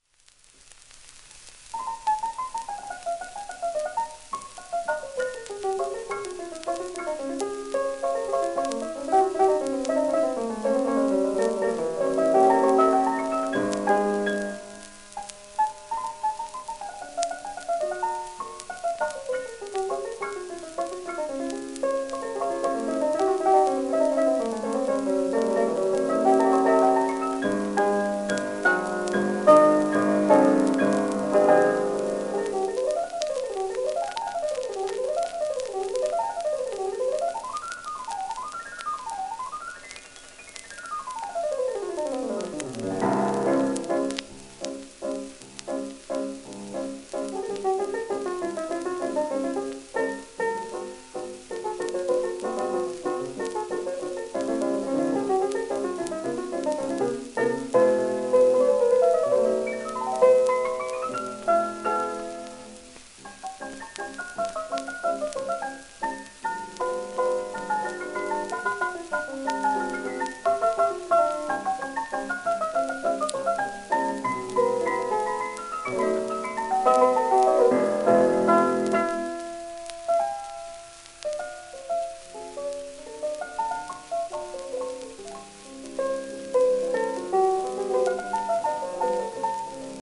※ピアノロールからのトラスクリプション
オーストリアの高名なピアニスト。